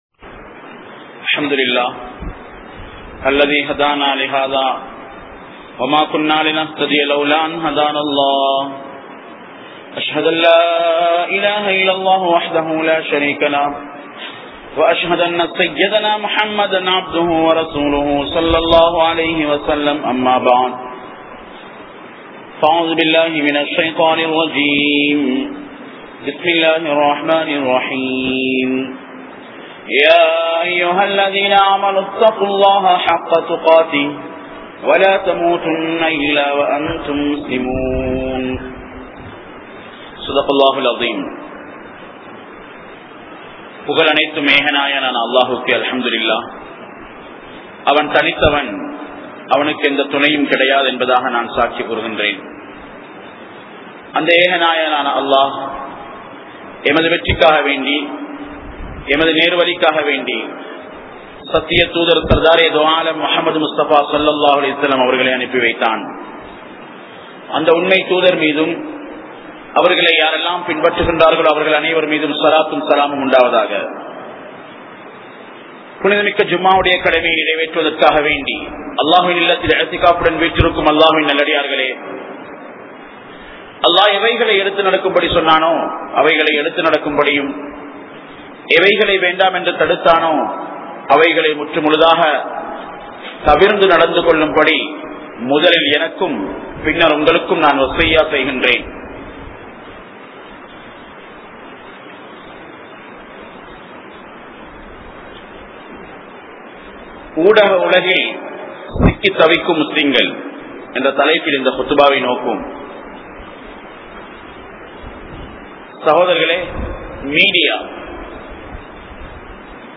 Media & Muslim Community | Audio Bayans | All Ceylon Muslim Youth Community | Addalaichenai